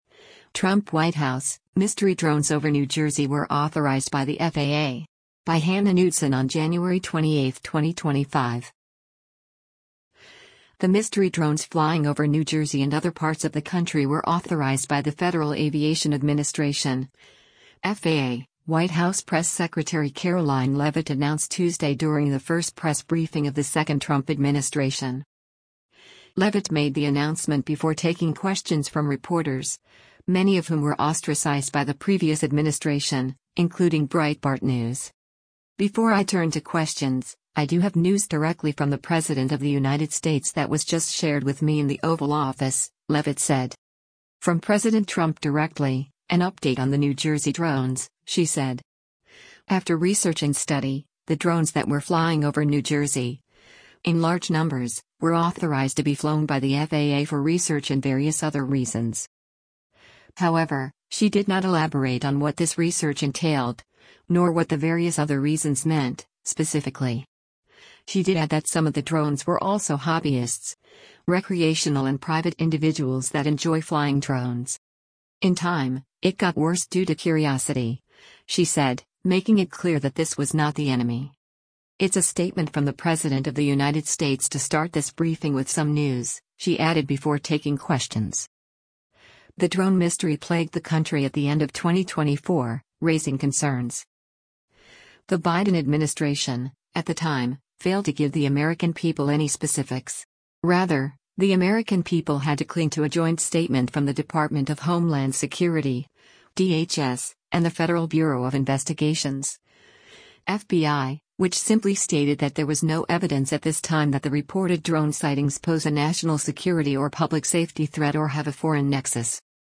The “mystery” drones flying over New Jersey and other parts of the country were authorized by the Federal Aviation Administration (FAA), White House Press Secretary Karoline Leavitt announced Tuesday during the first press briefing of the second Trump administration.